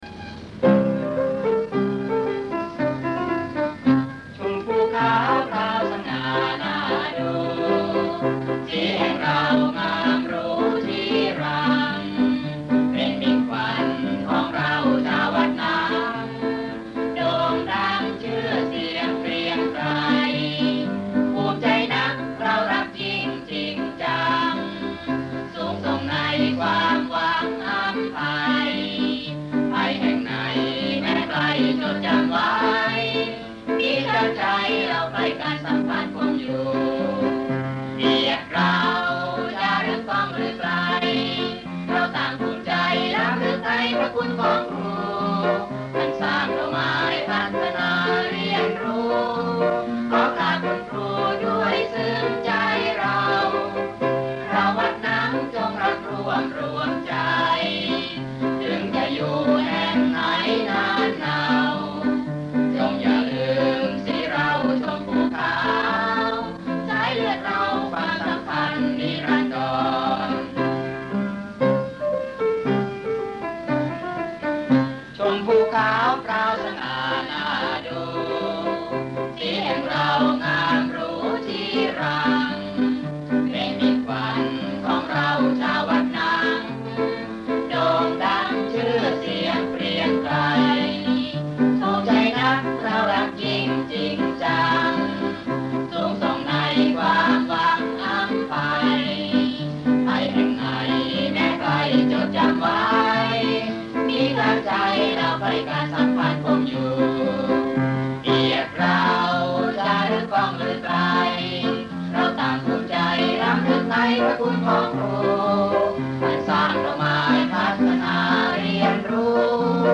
มาร์ช